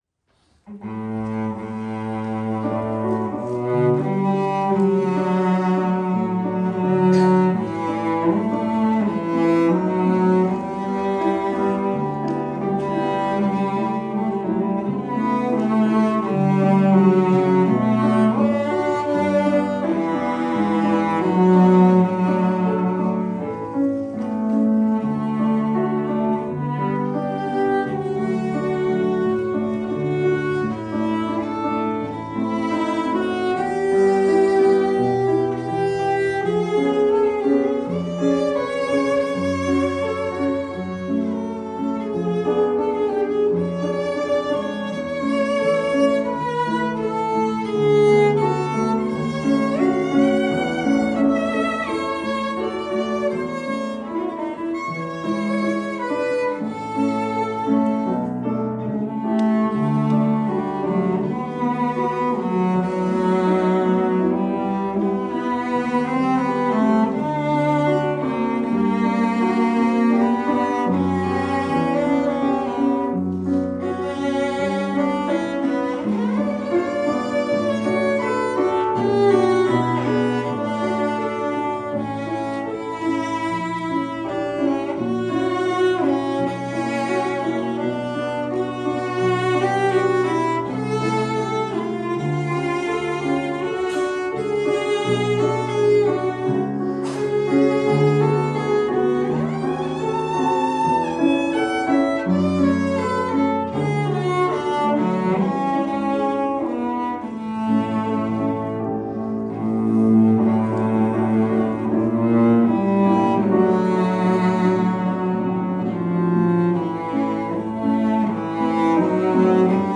Cello Recital - Cavatina - after a rainy day walk
In the evening I had a good cello recital.
playing cello and piano accompanist to side
I really enjoy this piece because it has a lovely melody, and I like that it covers the full range of the instrument.